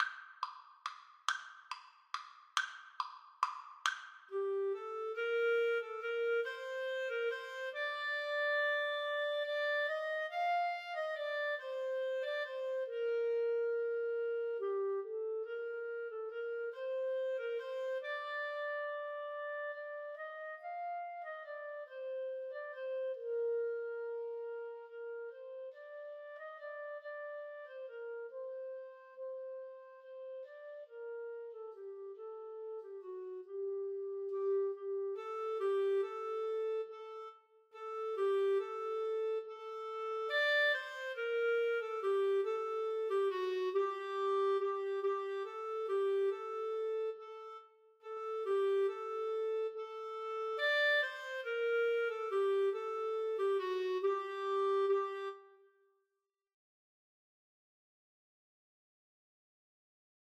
Clarinet 1Clarinet 2
3/4 (View more 3/4 Music)
=140 Andantino (View more music marked Andantino)
Classical (View more Classical Clarinet Duet Music)